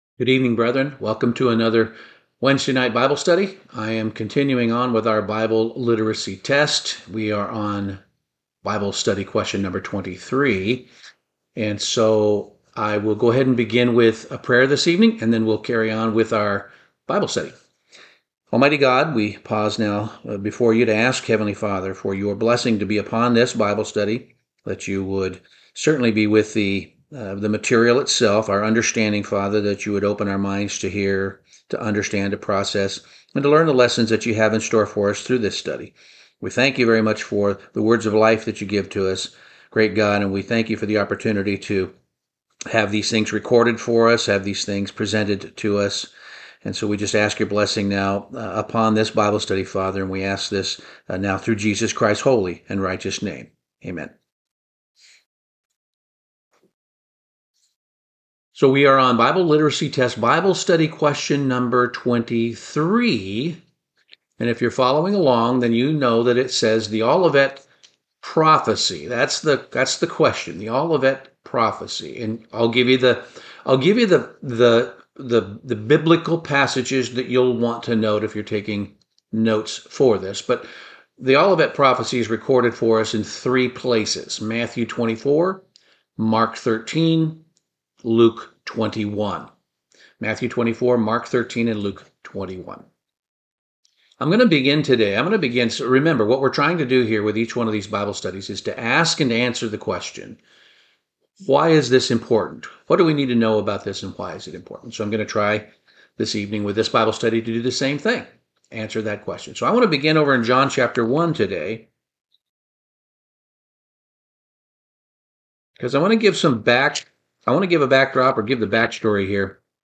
Bible Study - The Olivette Prophecy